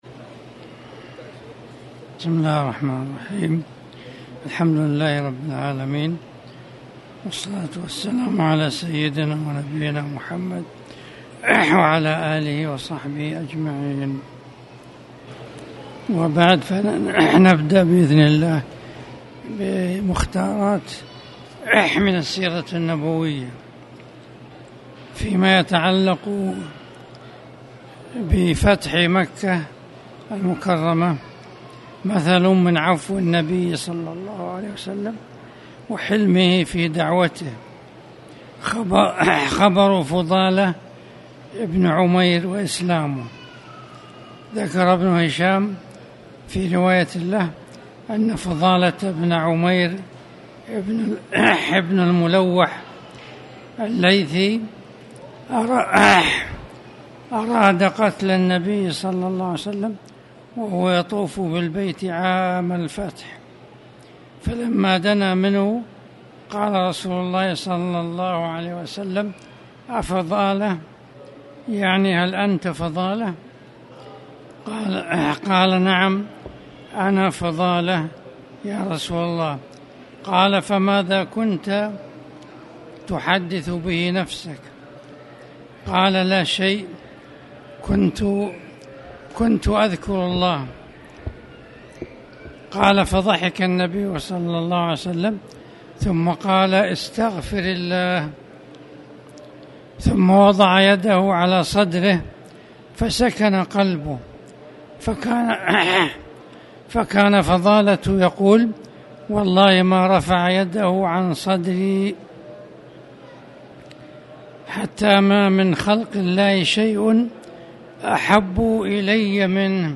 تاريخ النشر ٢ ذو القعدة ١٤٣٩ هـ المكان: المسجد الحرام الشيخ